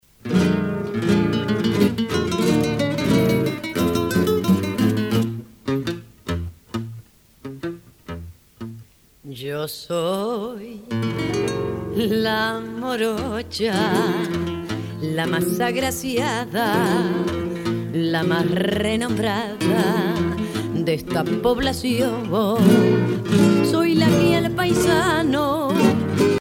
danse : tango (Argentine, Uruguay)